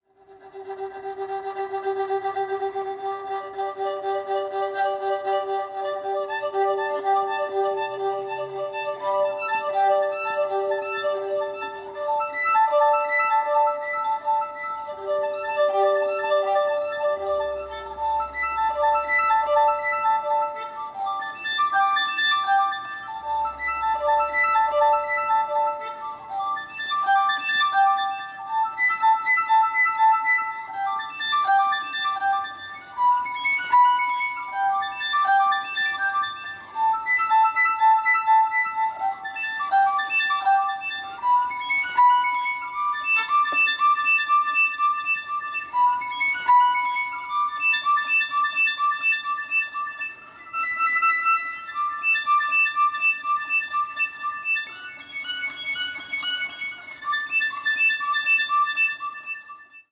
violin and electronics